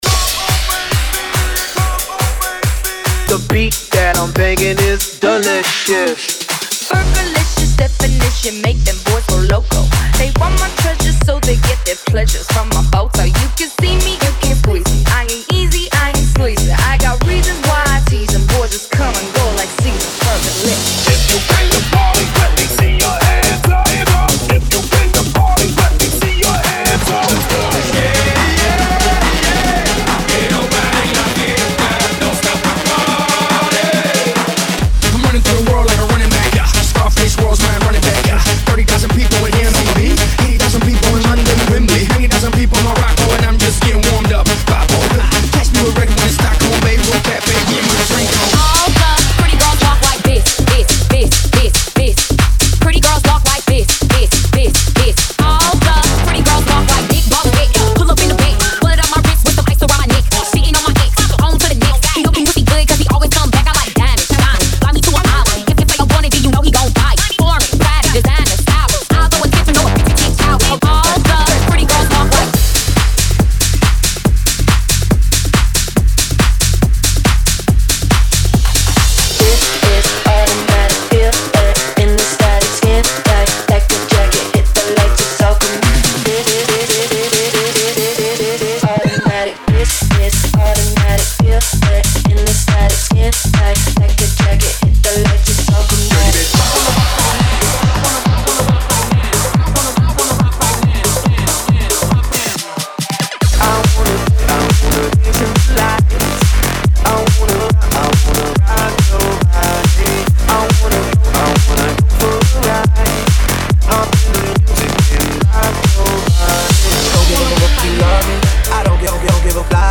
New 2026 Remixes & Reworked Edits keep the class energy!